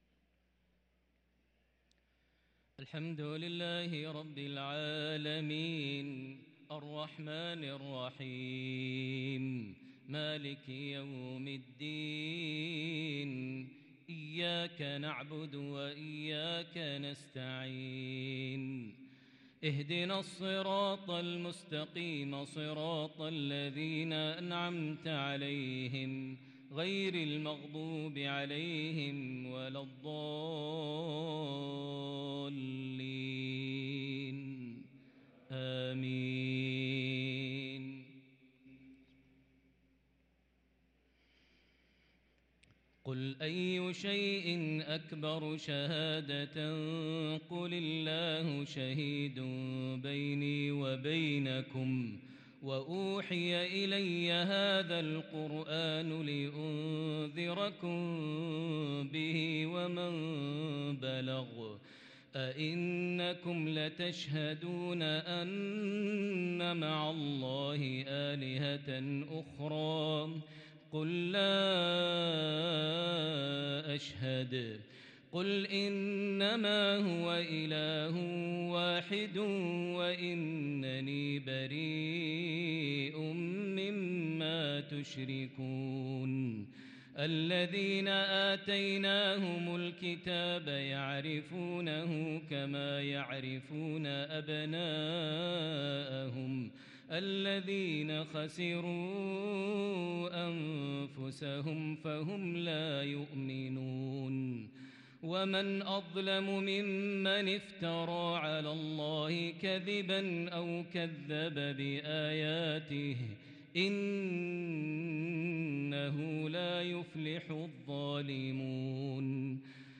صلاة العشاء للقارئ ماهر المعيقلي 12 ربيع الآخر 1444 هـ